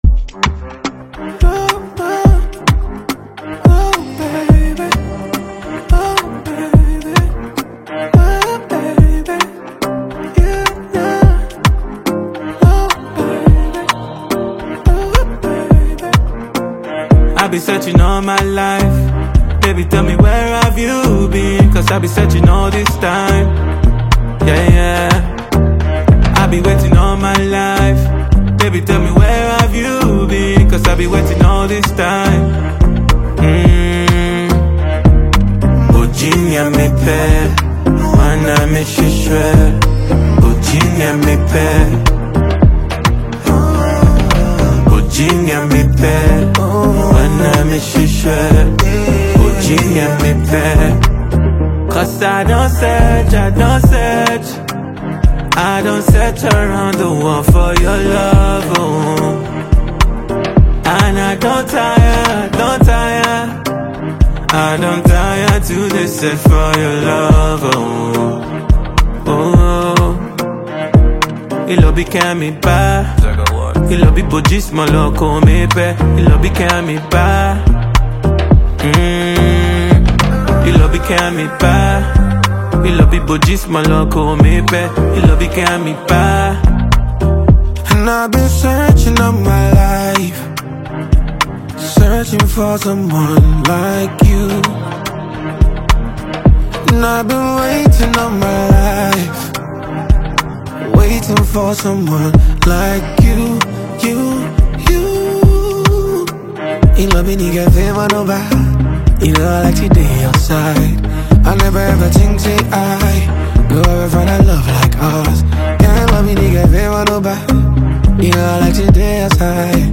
mid-tempo Afro-fusion pocket